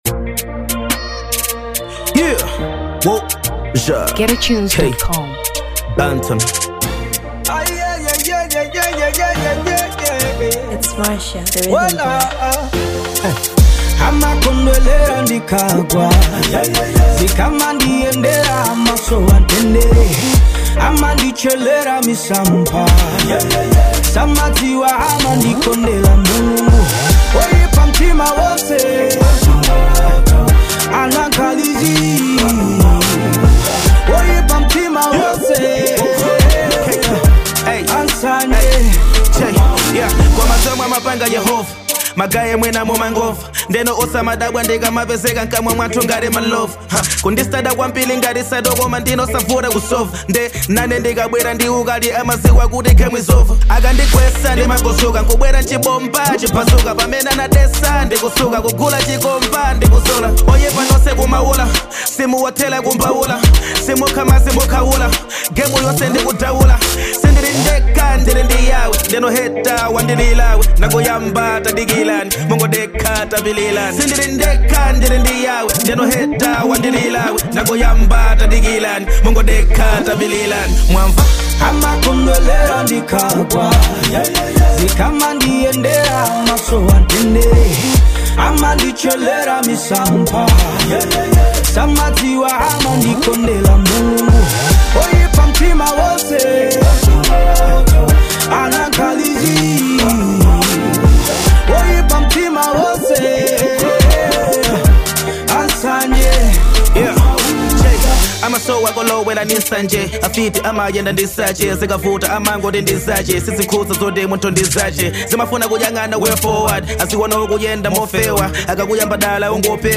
Hip Hop 2023 Malawi